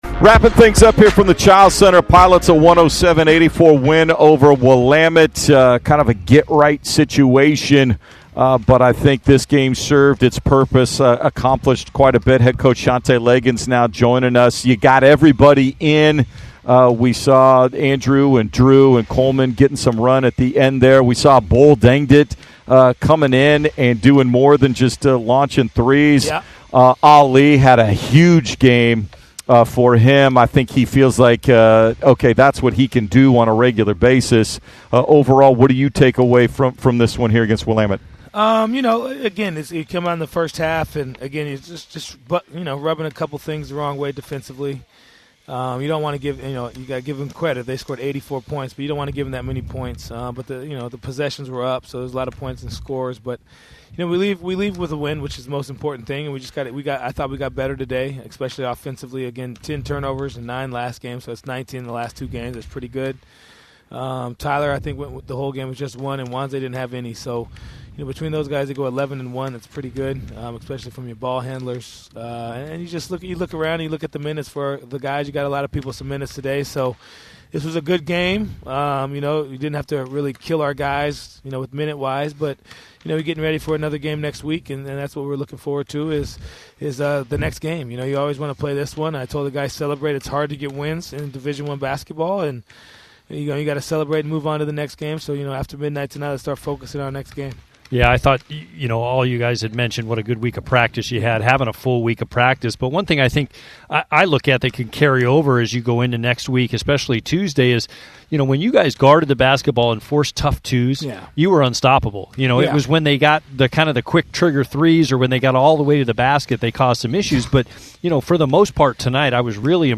Postgame vs. Willamette
Men's Basketball Post-Game Highlights